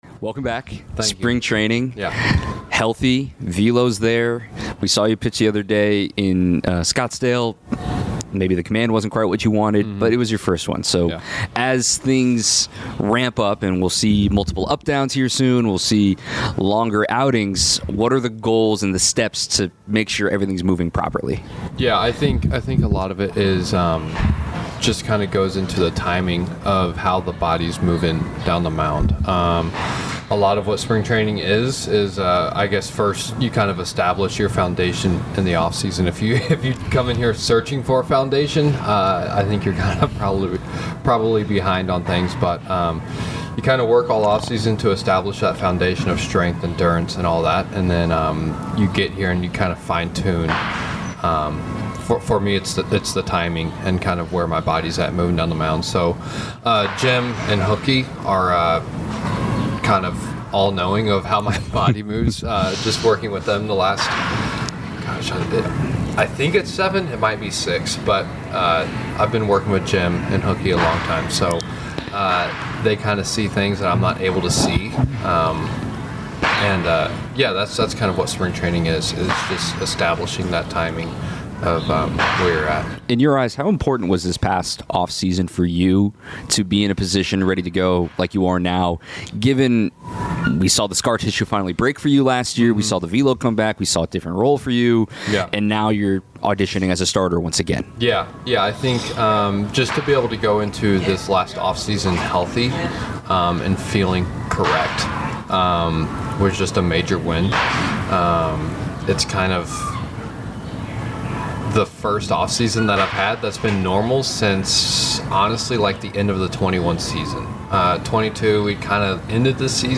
Brewers All Access - Aaron Ashby LIVE from Spring Training 2025